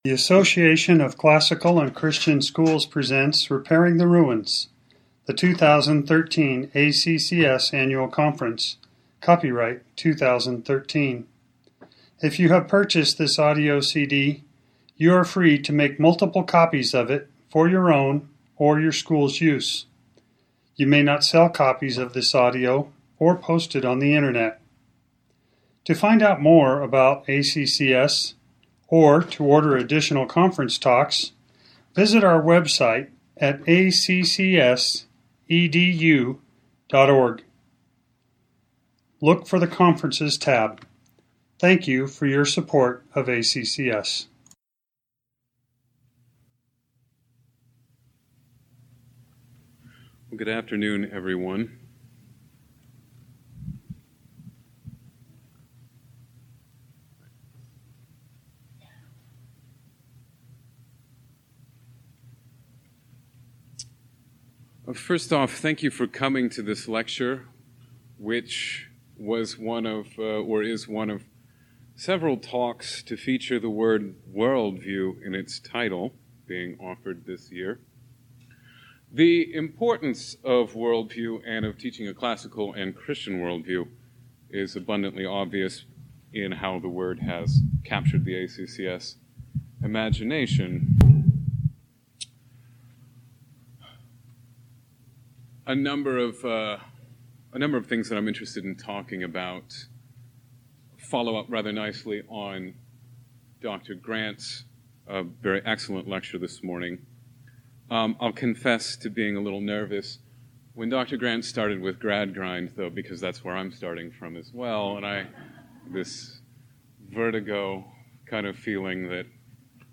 2013 Workshop Talk | 1:01:11 | All Grade Levels, Literature
Jan 21, 2019 | All Grade Levels, Conference Talks, Library, Literature, Media_Audio, Workshop Talk | 0 comments
The Association of Classical & Christian Schools presents Repairing the Ruins, the ACCS annual conference, copyright ACCS.